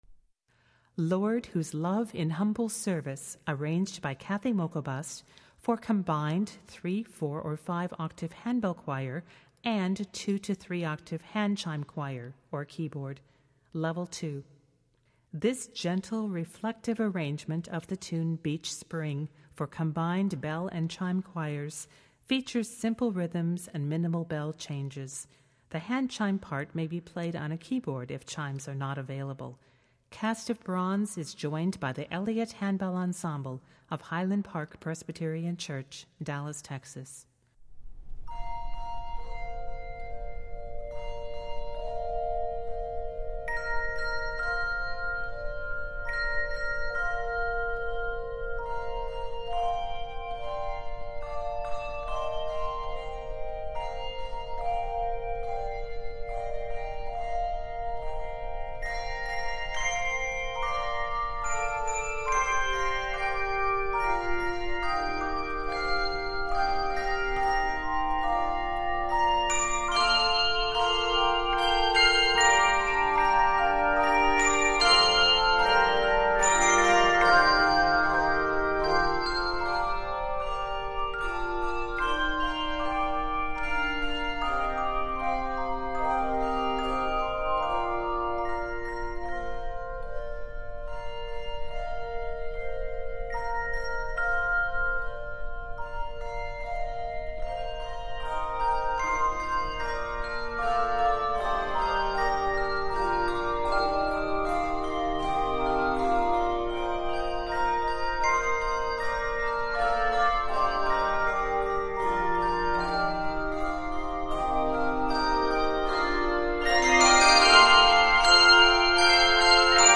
Voicing: Handbells 3-5 Octave